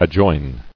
[ad·join]